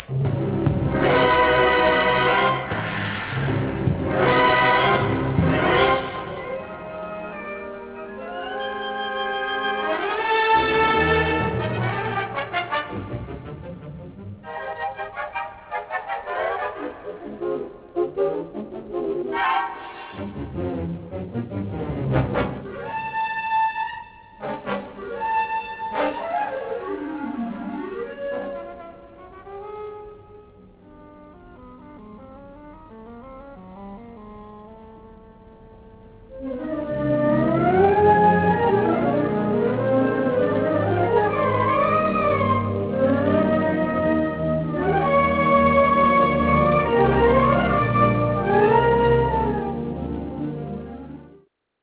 (Cuadros Sinfonicos)